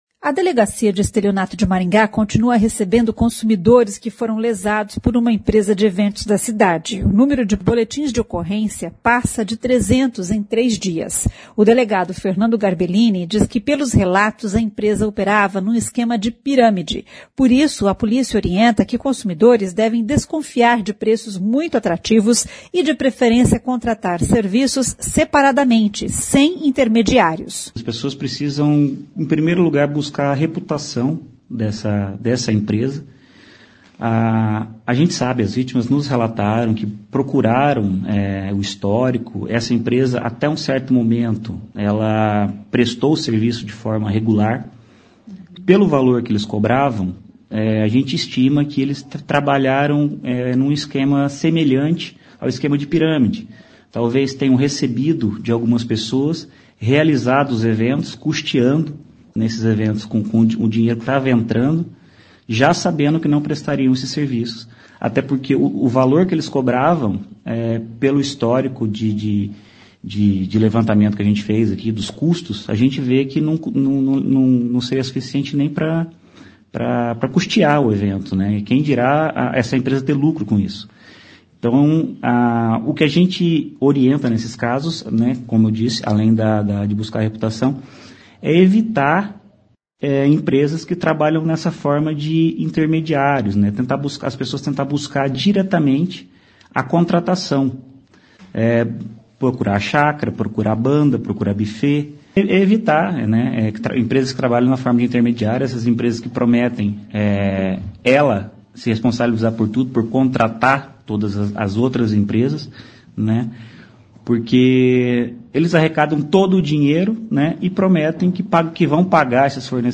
A CBN também conversou com especialista que dá dicas para garantir que tudo corra bem na realização de um evento.